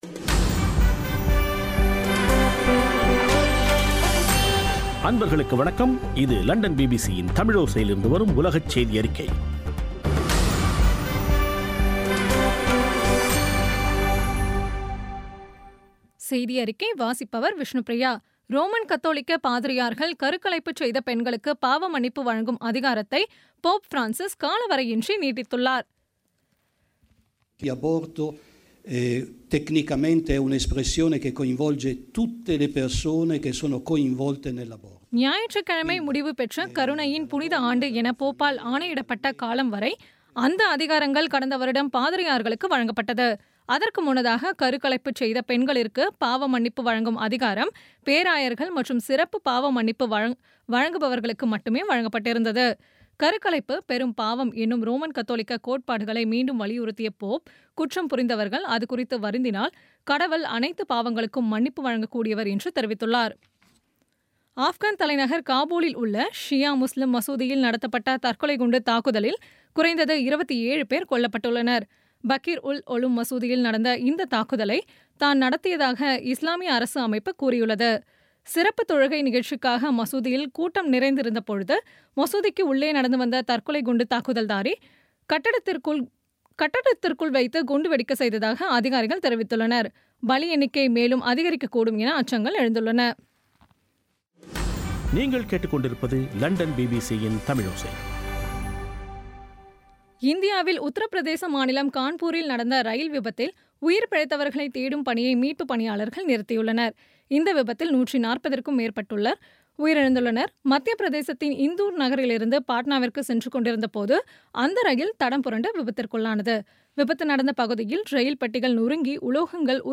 பி பி சி தமிழோசை செய்தியறிக்கை